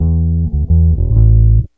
BASS 25.wav